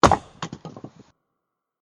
Single Log Chop
Single Log Chop is a free sfx sound effect available for download in MP3 format.
Single Log Chop.mp3